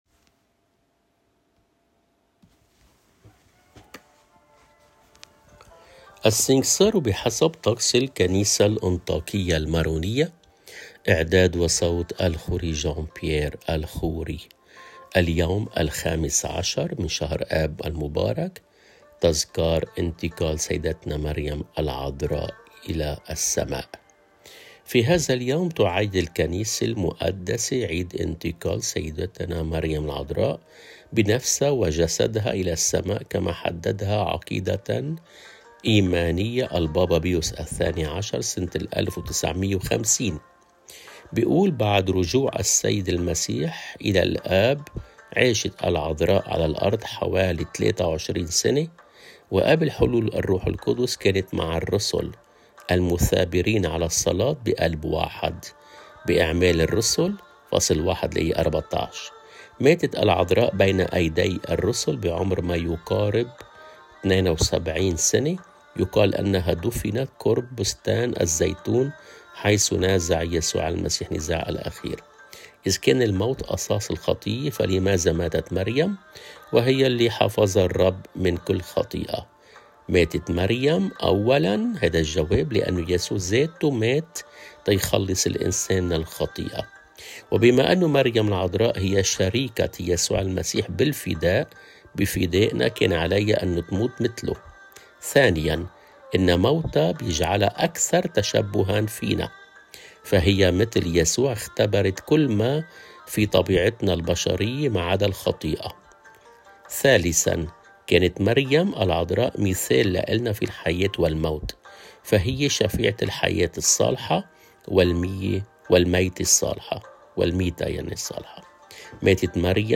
إنتقال سيّدتنا مريم العذراء «قدّيس اليوم» | khoddam El Rab